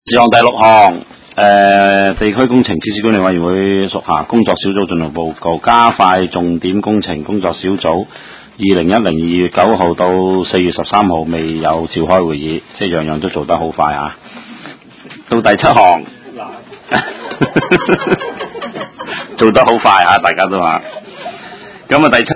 地區工程及設施管理委員會第十五次會議
灣仔民政事務處區議會會議室